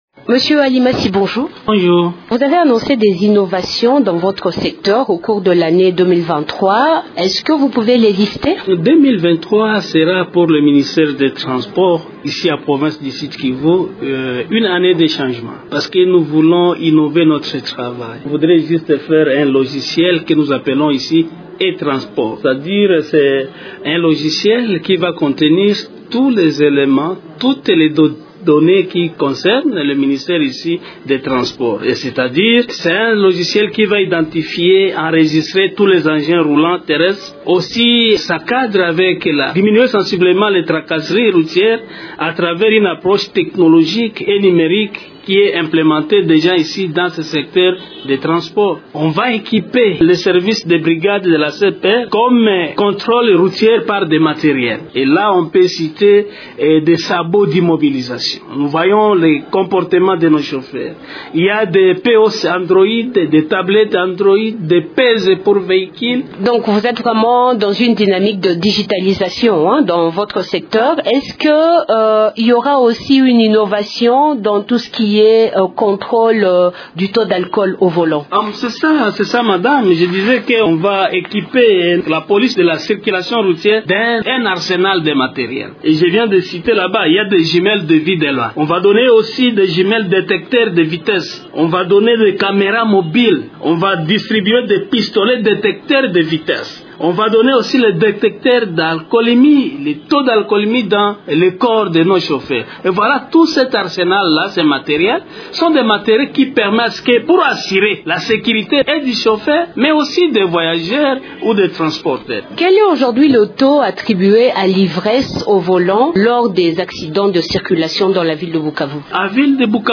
L’annonce est faite par Mathieu Alimasi, ministre provincial des transports du Sud-Kivu au cours d’une interview accordée à Radio Okapi.